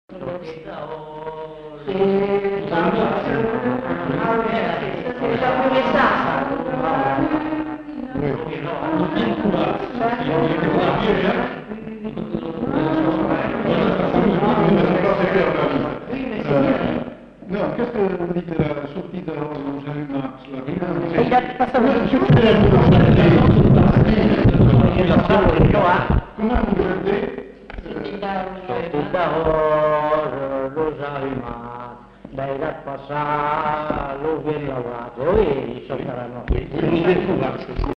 Lieu : Cazalis
Genre : chant
Effectif : 1
Type de voix : voix d'homme
Production du son : chanté